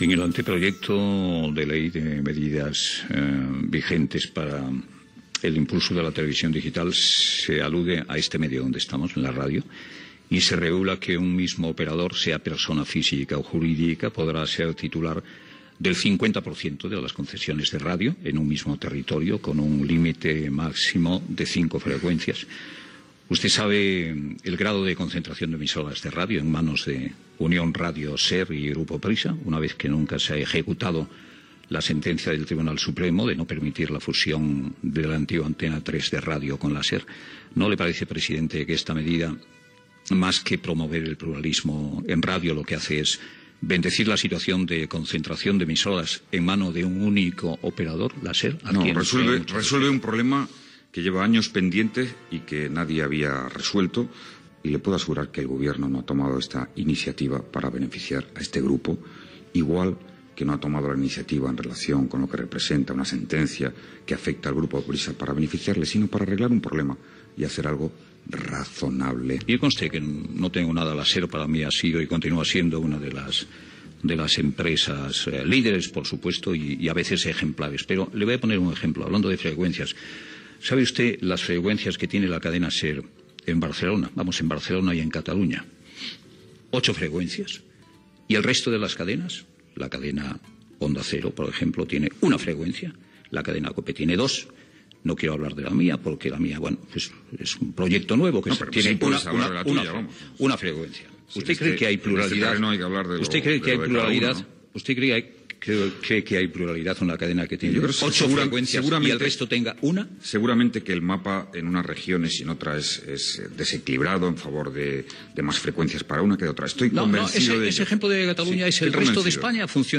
Fragment d'una entrevista al president del govern espanyol José Luis Rodríguez Zapatero, al Palau de la Moncloa. Comentari sobre la concentració de freqüències d'emissores en mans d'un mateix propietari (Prisa).
Info-entreteniment